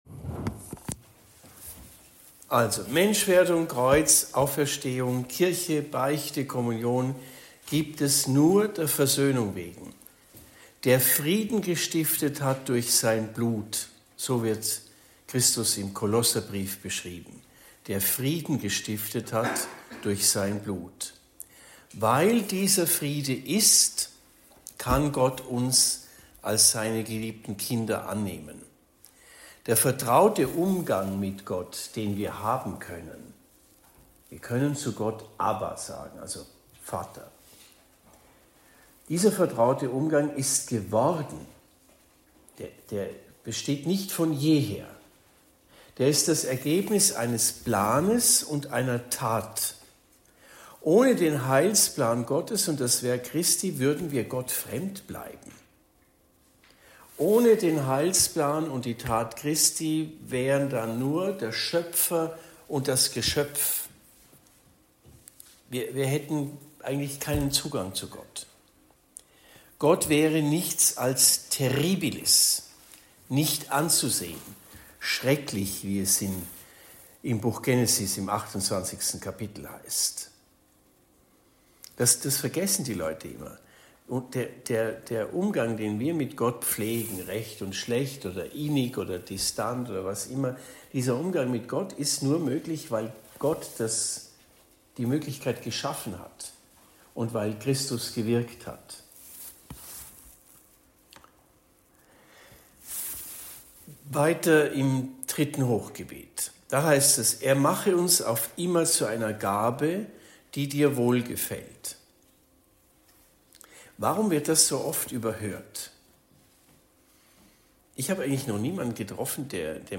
Hingabe und Wandlung II – Vortrag bei den Ordensexerzitien in Stift Schlägl